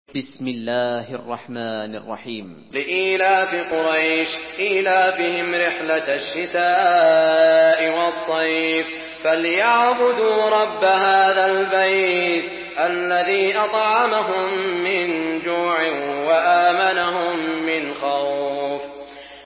Surah ক্বুরাইশ MP3 by Saud Al Shuraim in Hafs An Asim narration.
Murattal